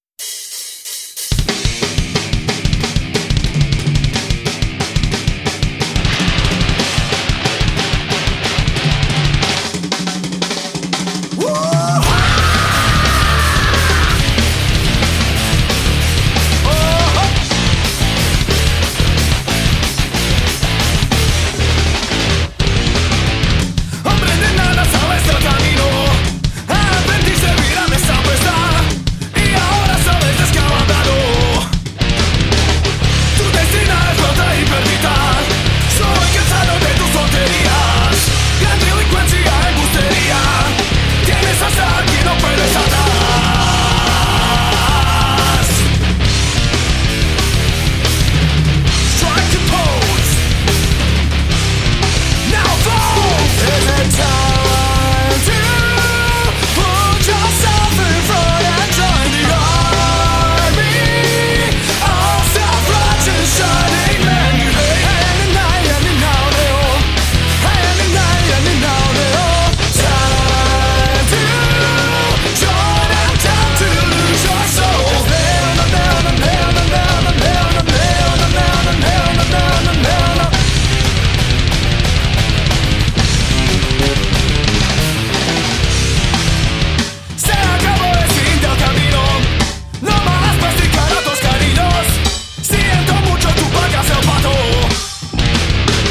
vokal
kitara
bobni
bas-kitara